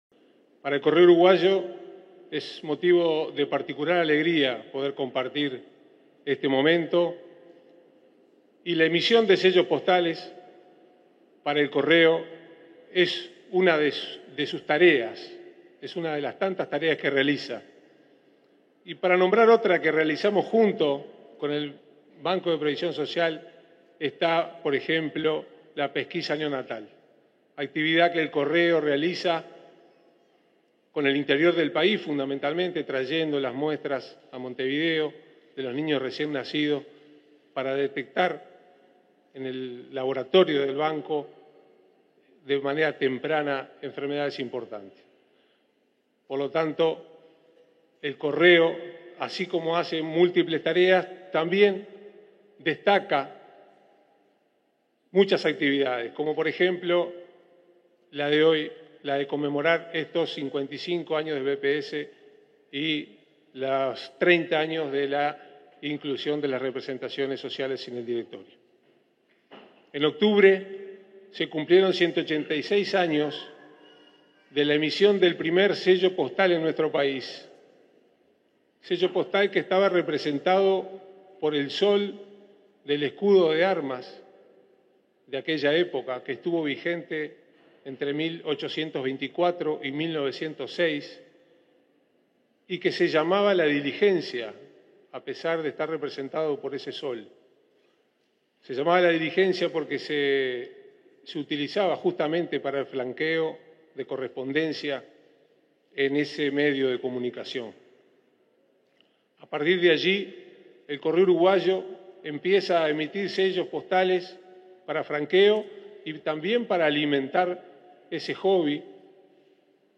Palabras de los presidentes del BPS y del Correo Uruguayo
Participaron del evento, el presidente del BPS, Alfredo Cabrera, y el presidente del Correo Uruguayo, Rafael Navarrine.